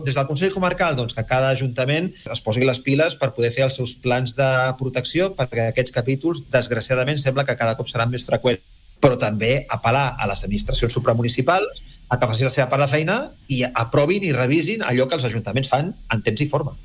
Calella espera que Protecció Civil homologui el pla únic d’actuació municipal davant situacions de risc o d’emergències, que es va aprovar definitivament el passat mes de març. Consta com a pendent, una situació en la que també es troben d’altres poblacions de la comarca, tal com ha assenyalat el vicepresident primer del Consell Comarcal, Rafa Navarro, que és el responsable d’Acció climàtica de l’ens, en una entrevista al magazine A l’FM i +.